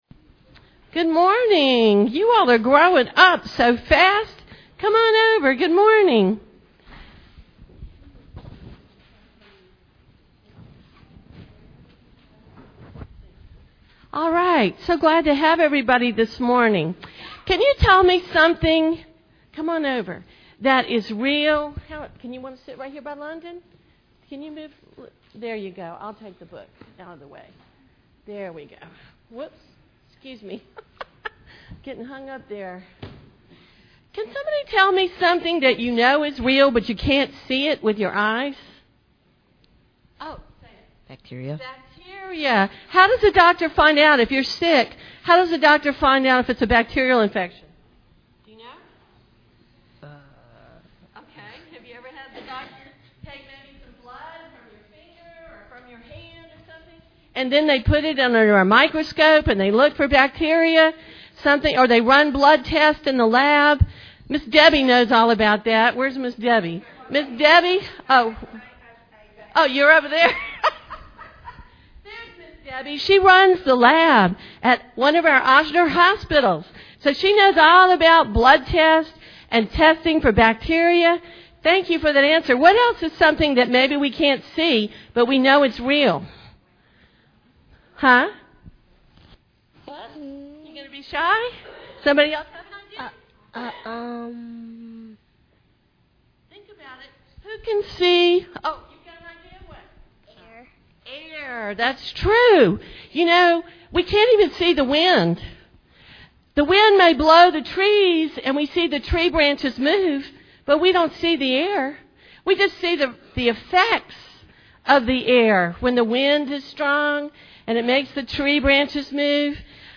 Children's Sermon